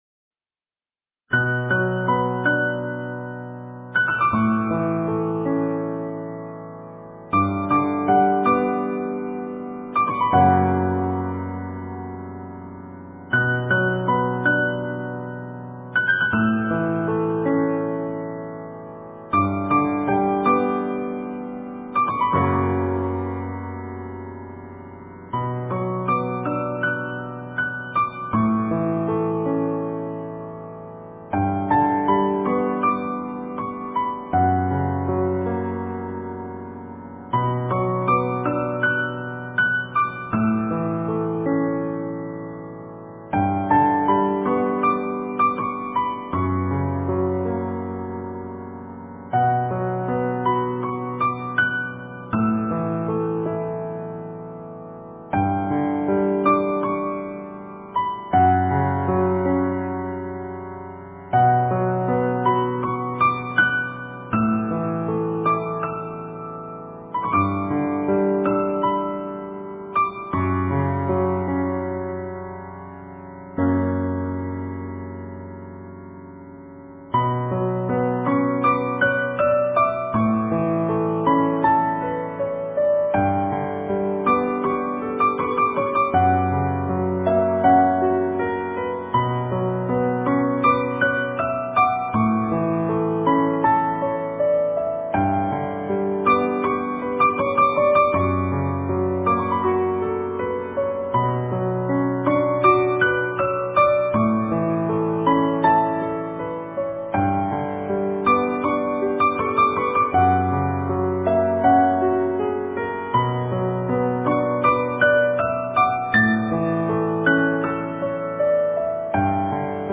标签: 佛音 冥想 佛教音乐